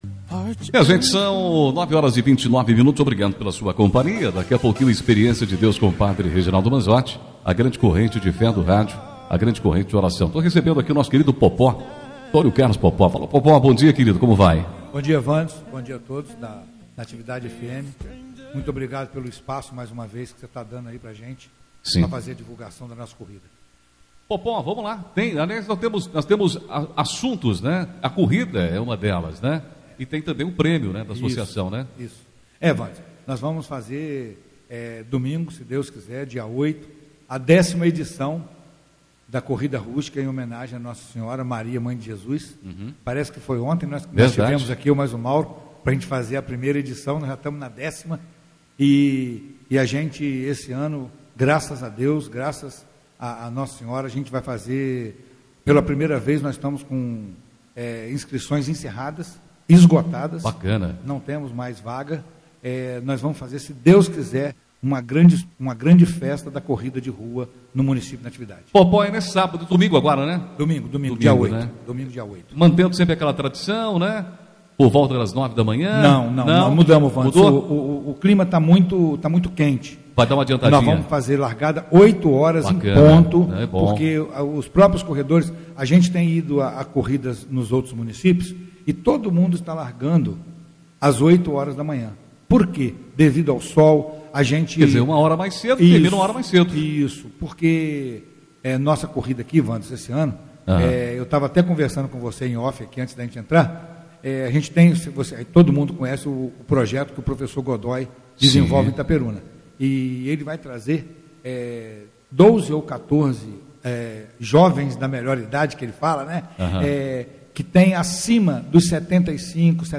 3 de dezembro de 2024 ENTREVISTAS, NATIVIDADE AGORA